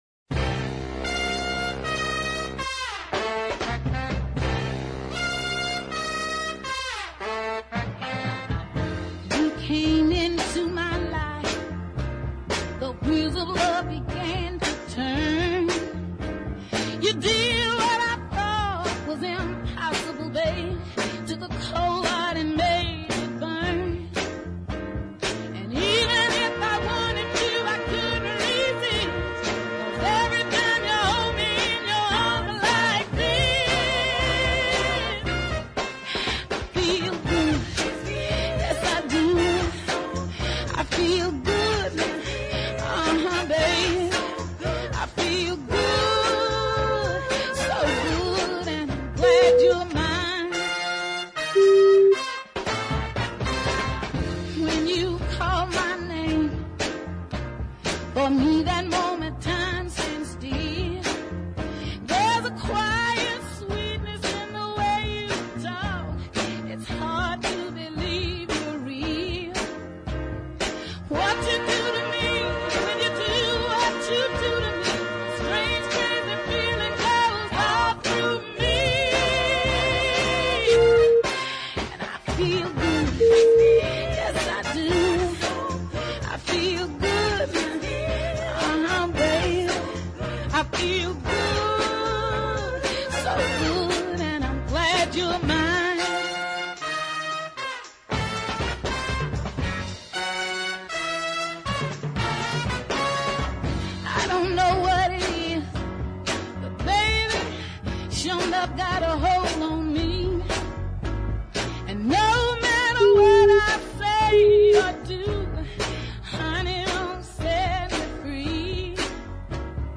we return to another truly great female soul voice.